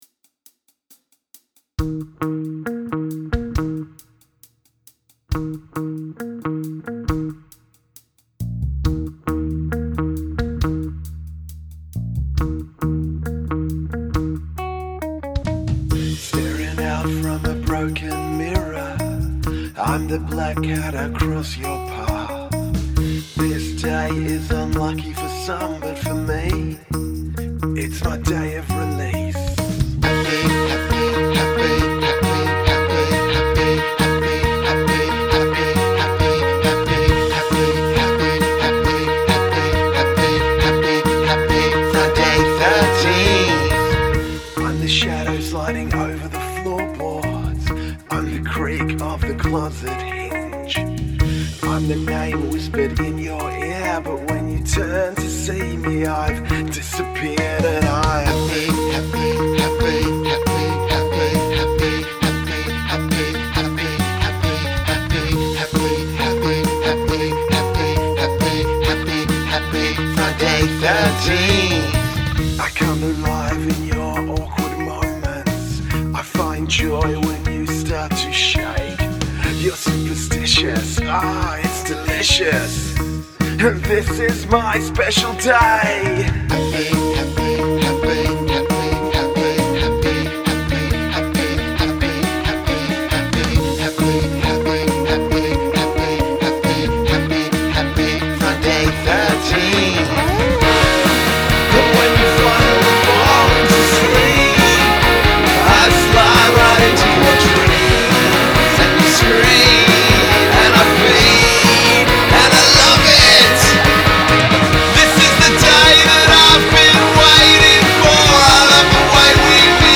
I love the climax - really good build-up to that.